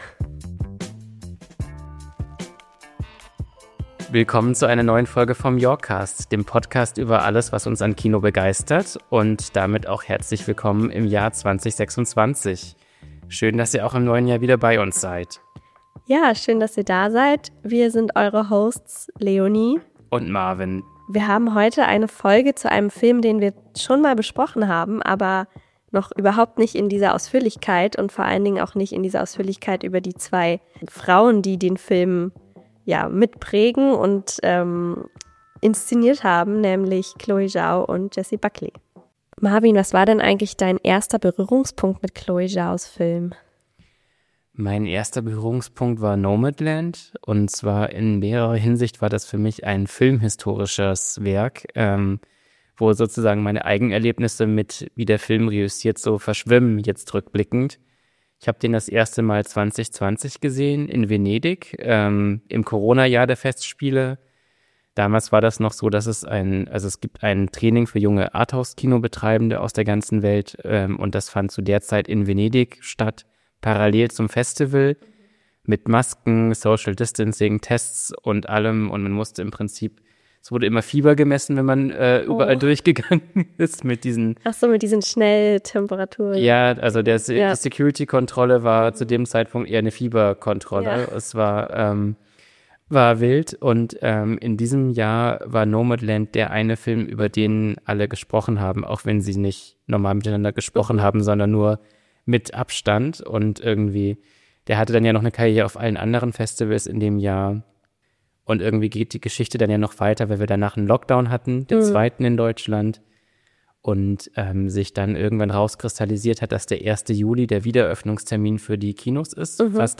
Beschreibung vor 3 Monaten Wir können es noch gar nicht richtig fassen: Für diese Episode durften wir Chloé Zhao und Jessie Buckley zum Interview treffen – unser allererstes Gespräch mit internationalen Filmstars im Yorckcast! Passend zum deutschen Kinostart und frisch nach den Oscar-Nominierungen tauchen wir noch einmal tief in HAMNET ein.